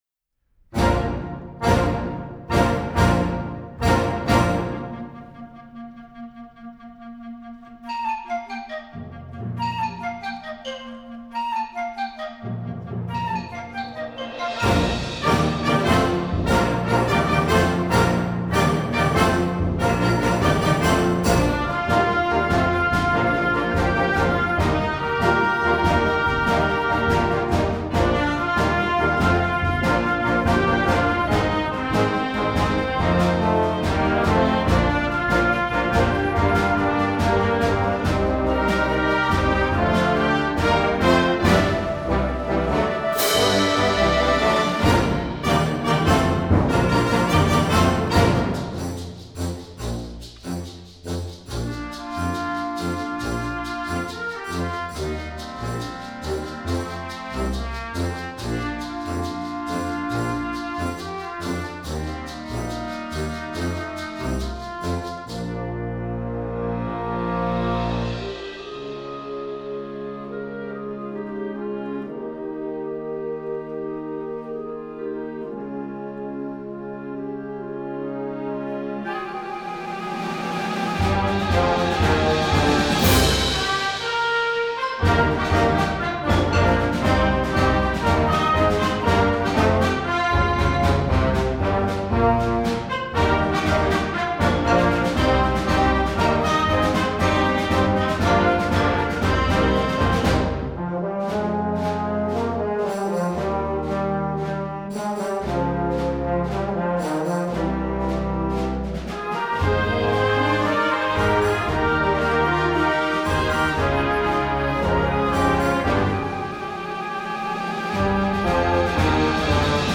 Gattung: Medley
Besetzung: Blasorchester
Three gallant tunes are intertwined